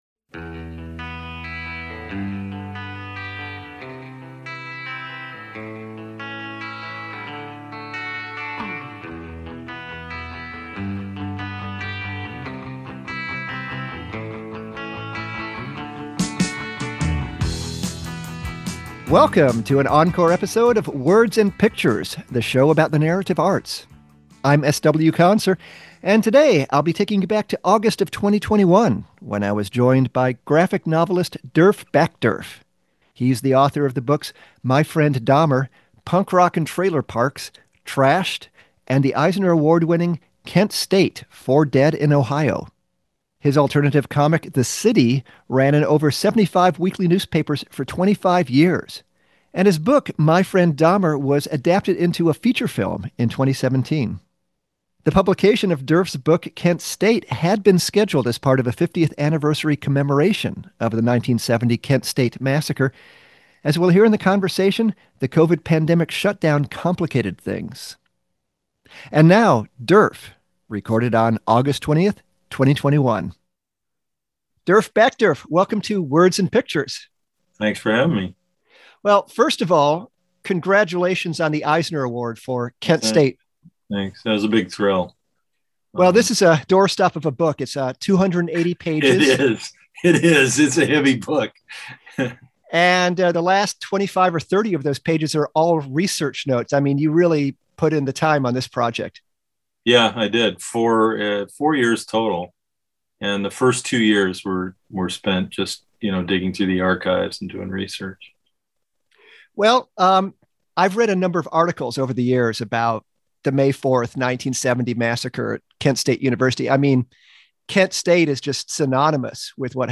With the deployment of National Guard troops once again in the news, we revisit our August 2021 conversation with graphic novelist Derf Backderf.